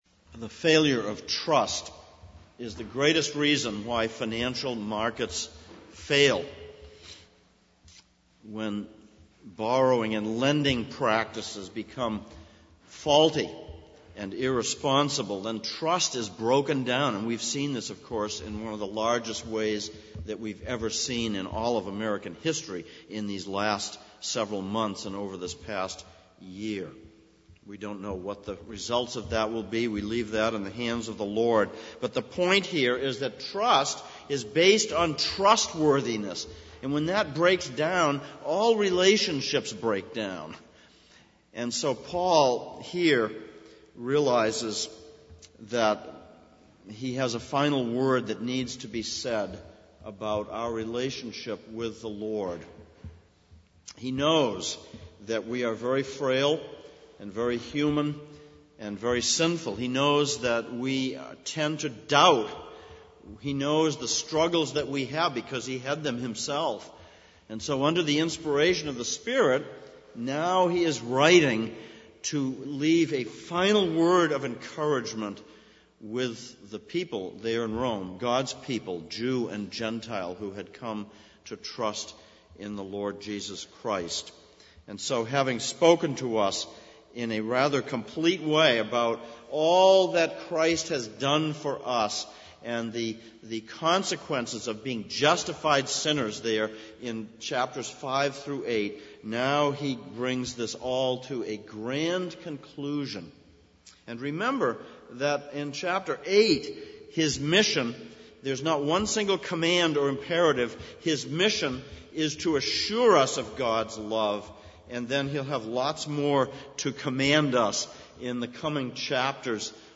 Exposition of Romans Passage: Romans 8:31-39 Service Type: Sunday Morning « 2008